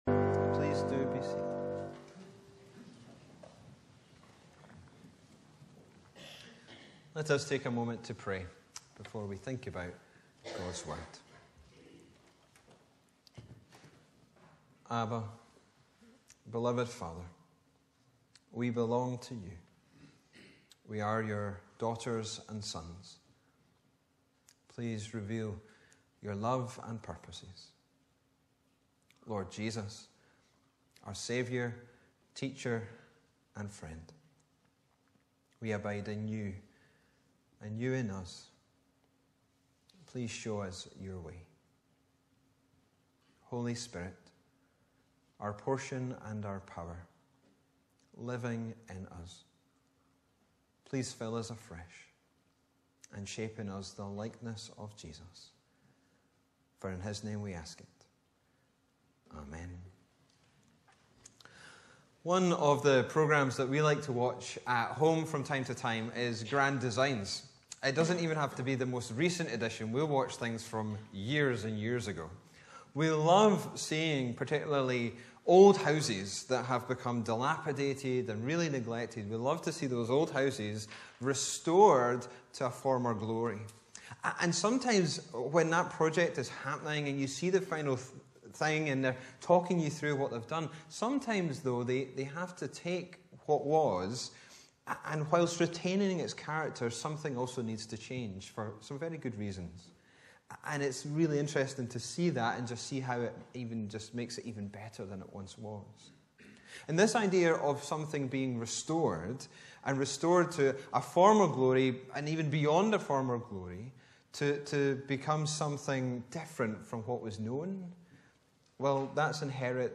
Bible references: Joel 2:18-29 Location: Brightons Parish Church Show sermon text Sermon keypoints: - Restoration is of The Lord - Restoration is dependent - Restoration is for a better future